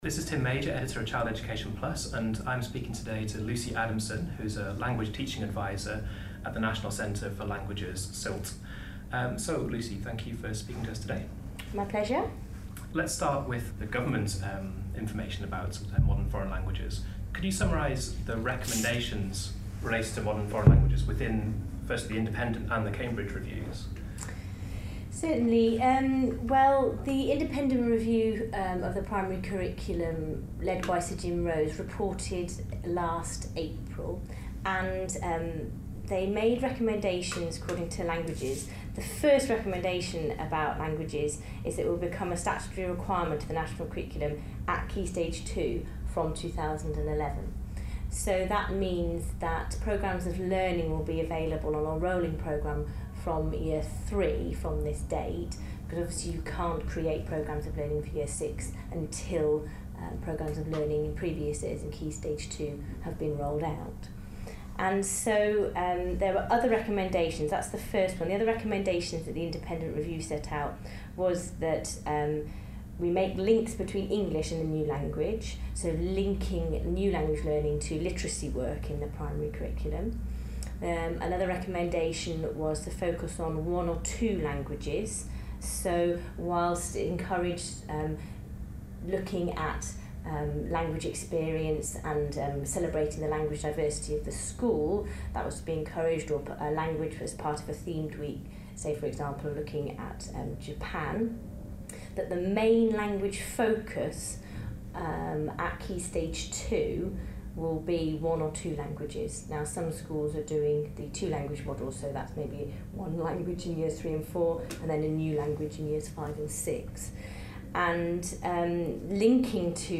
MFL - Interview